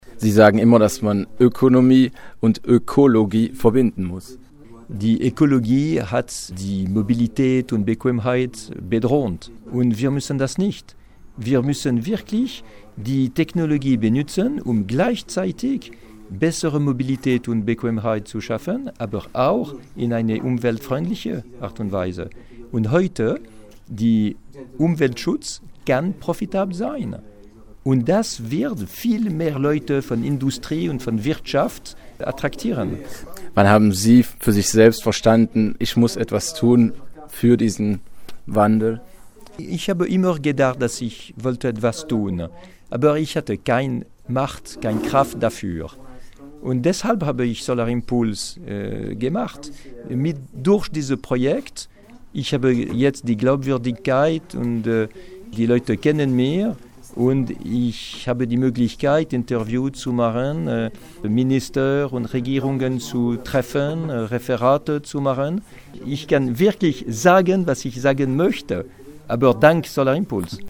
Bertrand Piccard hat als erster Mensch die Welt in einem Solarflugzeug umkreist. Am Freitag war der Luftfahrtpionier in Francorchamps bei den Spark#E-Days.